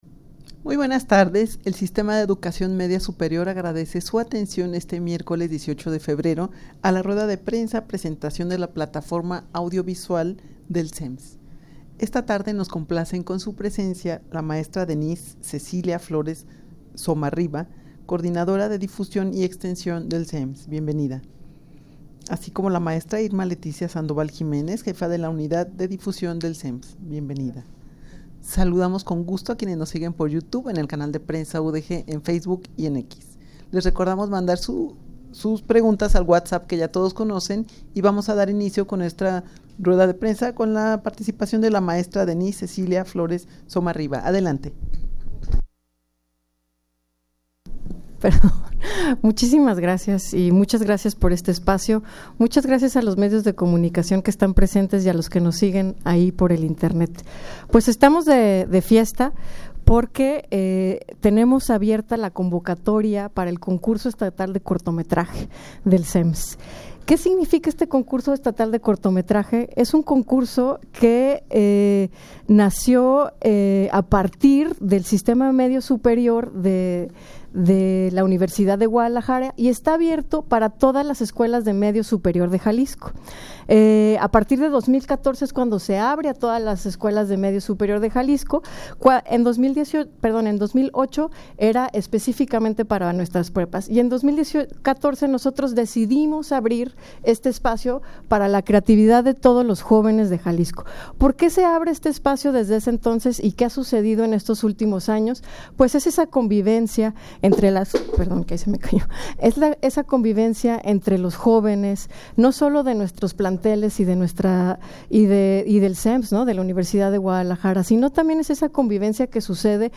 rueda-de-prensa-presentacion-de-la-plataforma-audiovisual-del-sems.mp3